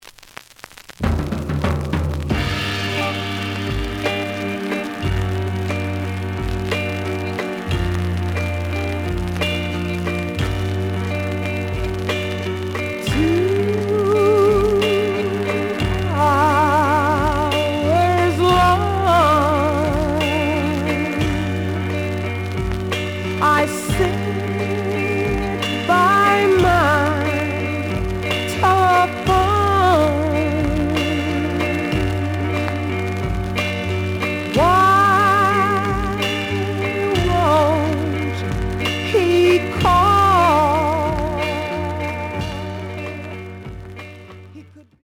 The audio sample is recorded from the actual item.
●Genre: Soul, 60's Soul
Some noise on both sides.)